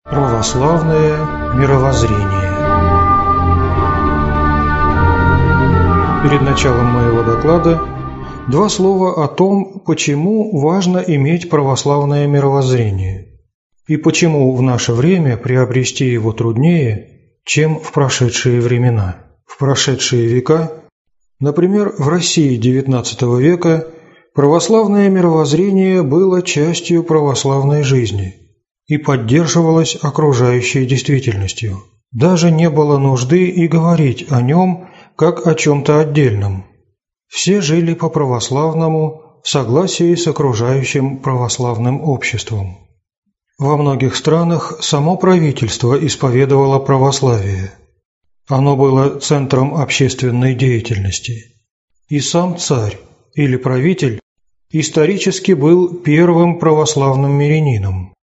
Аудиокнига Православное мировозрение | Библиотека аудиокниг